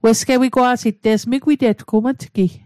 Pronunciation guide: wes·ko·wi·gwaa·sit